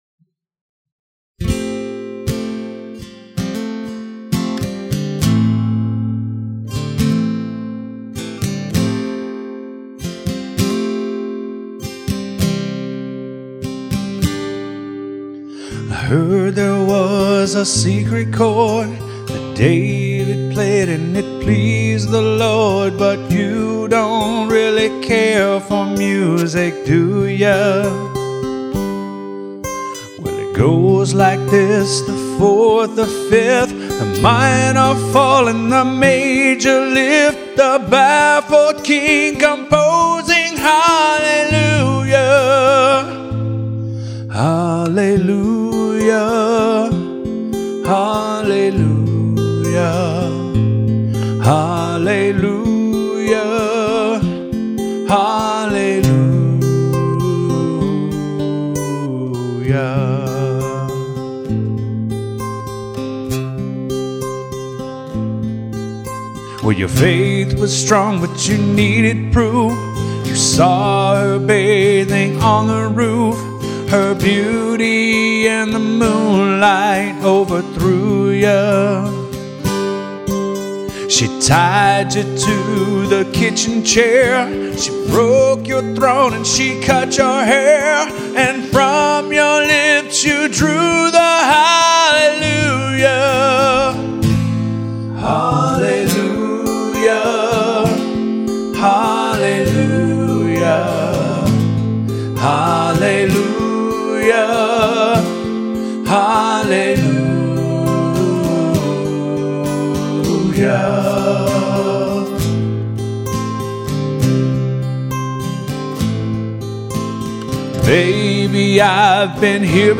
Acoustic Guitars
Vocals